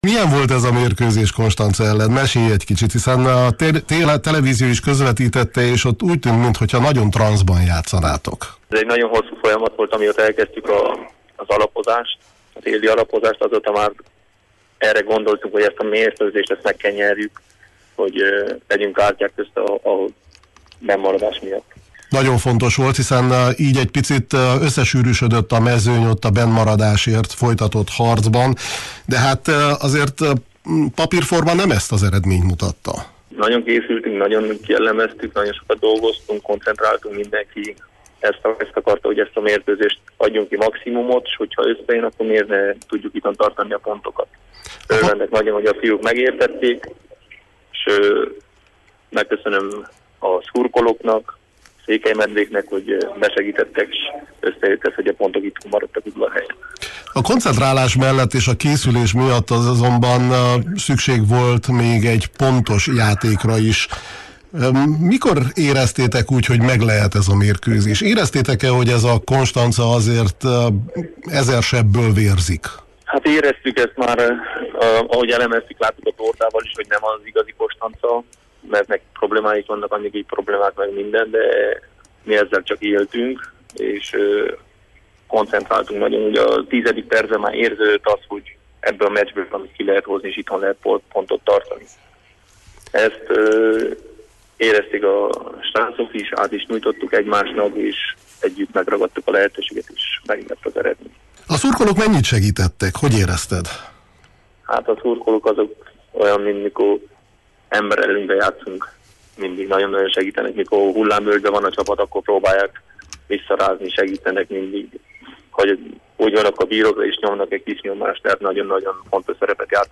beszélgetett a Kispad című sportműsorunkban: